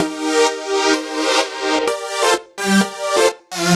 Index of /musicradar/french-house-chillout-samples/128bpm/Instruments
FHC_Pad B_128-C.wav